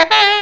COOL SAX 13.wav